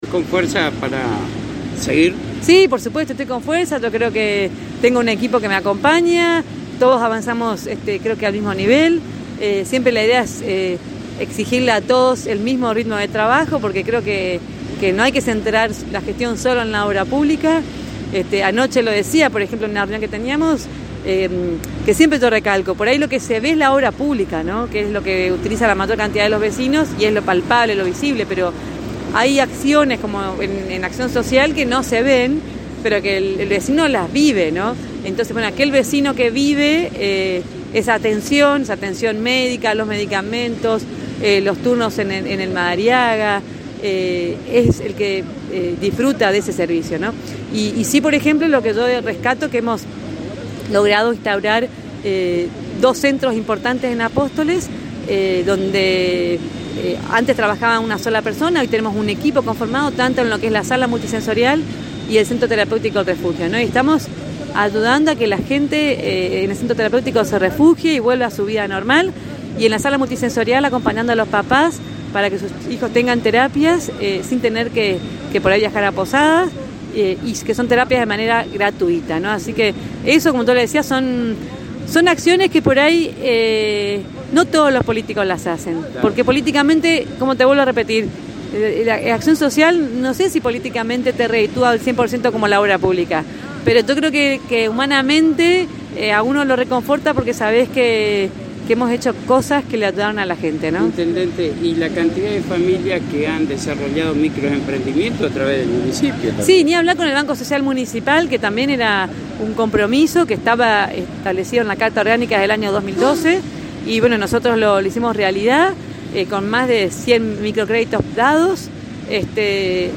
La Intendente de Apóstoles María Eugenia Safrán, en diálogo exclusivo con la ANG manifestó que en este año 2023 se trabajarán en tres puntos principales la construcción de cordón cuneta y empedrados en barrios puntuales, desagües, canalización de los desagües pluviales en lugares específicos, segundo finalización del acceso del Parque Industrial servicio de electricidad y […]